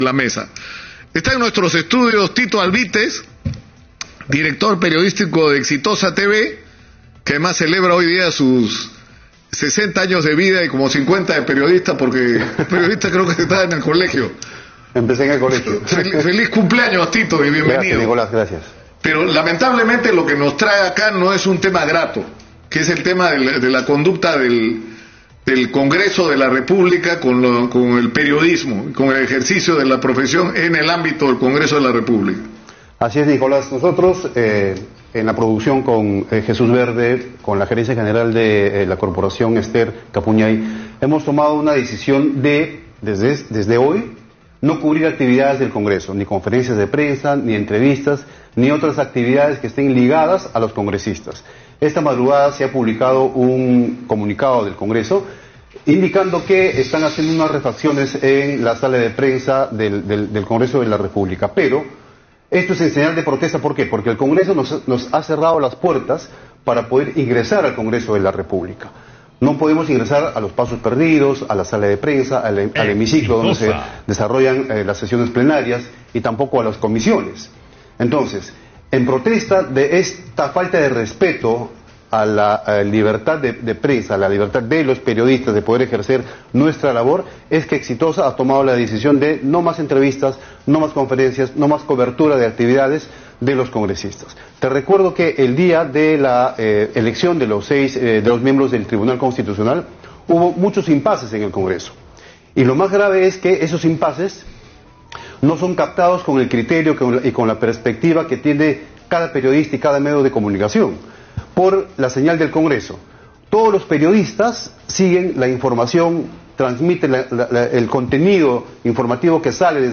Entrevista
por Radio Exitosa - Lima